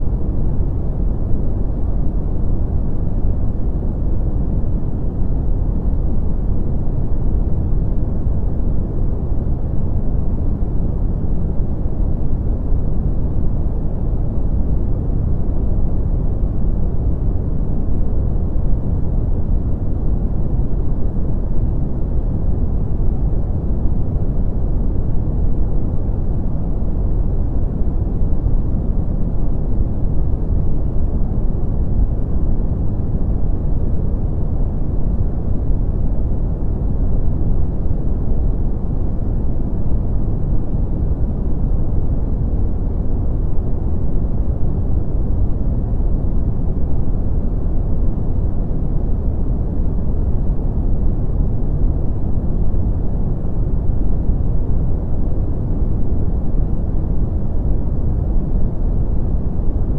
asmr hand movements no talking